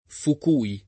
vai all'elenco alfabetico delle voci ingrandisci il carattere 100% rimpicciolisci il carattere stampa invia tramite posta elettronica codividi su Facebook Fukui [giapp. P uk 2 i ] top. (Giapp.) e cogn. — come top., anche con pn. italianizz. [ fuk 2 i ]